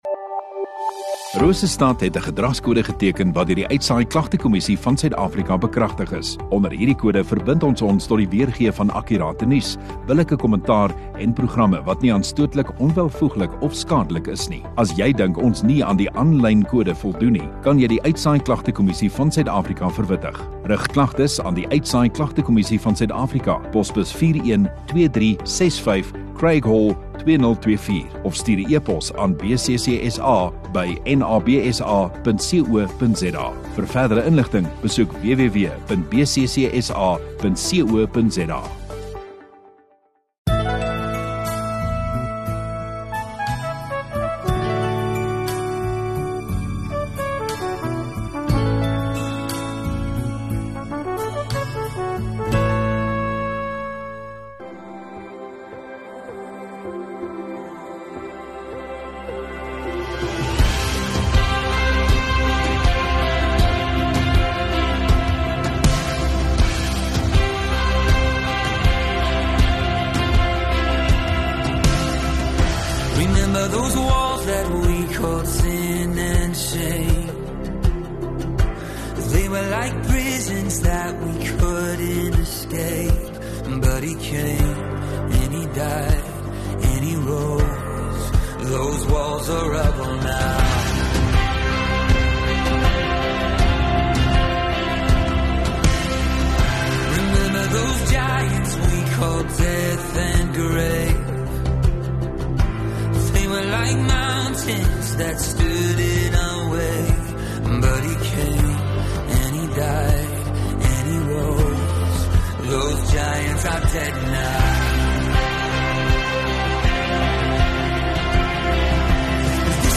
24 Dec Dinsdag Oggenddiens